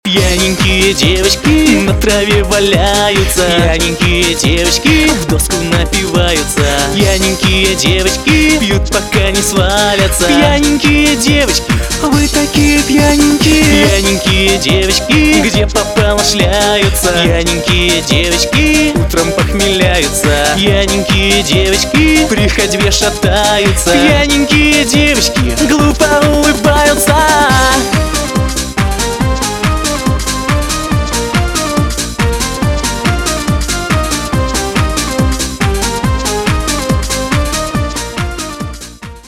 • Качество: 256, Stereo
смешные
с юмором